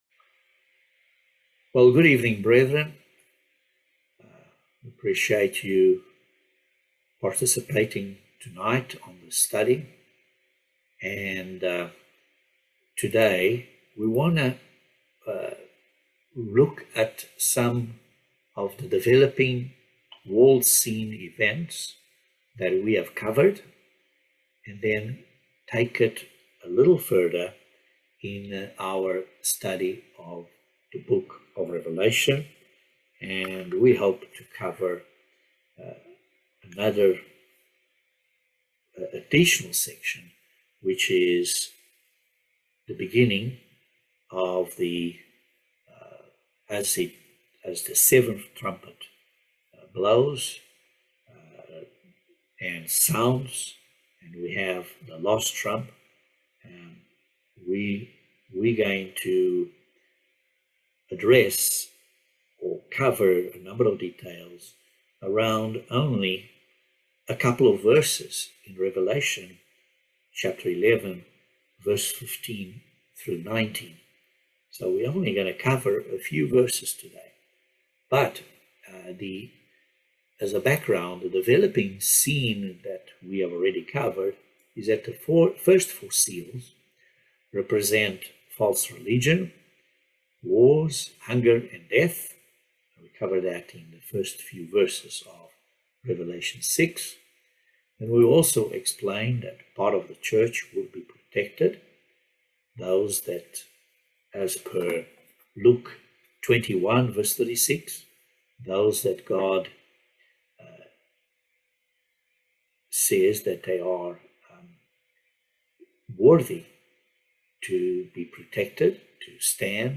Bible Study No 20 of Revelation